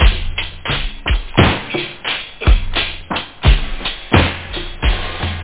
drumloop.quickfunckloop
Amiga 8-bit Sampled Voice
1 channel
drumloop.mp3